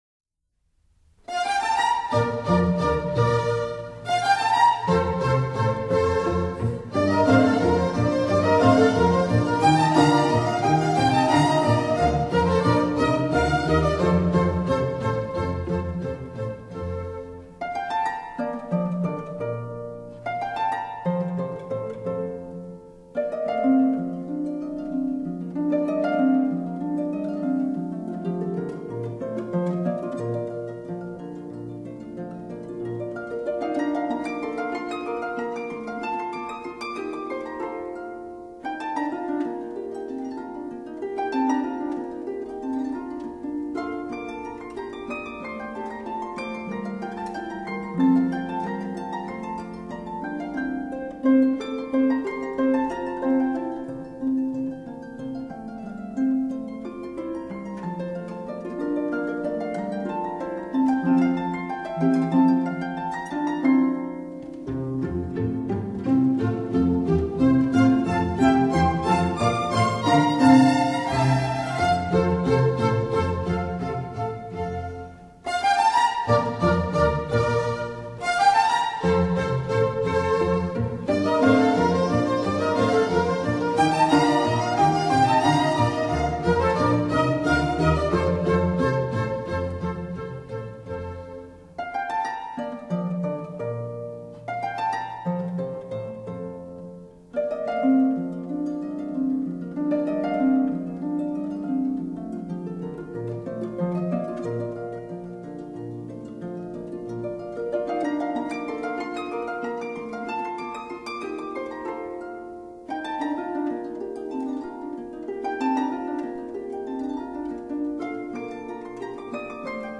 豎琴，被人譽爲從天上下凡的美聲樂器，奏出無比潔淨的聲音，聞者煩惱盡消，心情舒暢！